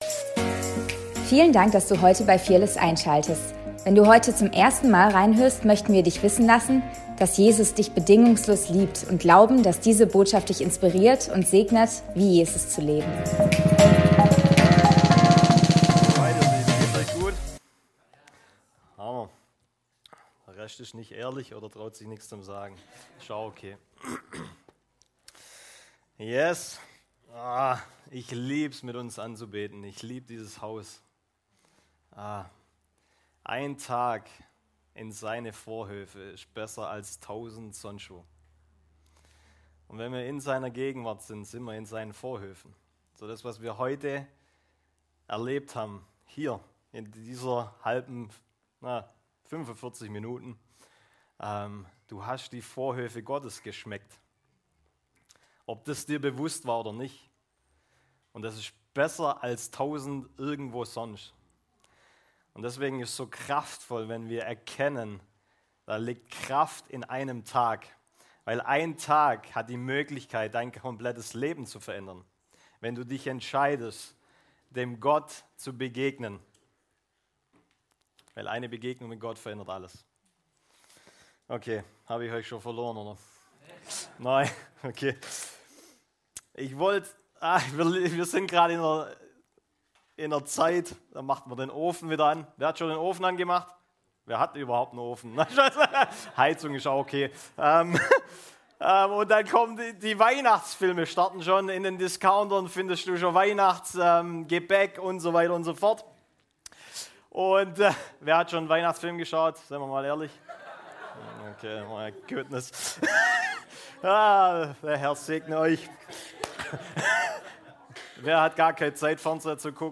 Predigt vom 06.10.2024